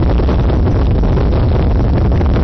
fl_hum.ogg